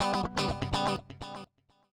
House / Guitar / ELECGUIT066_HOUSE_125_A_SC2(L).wav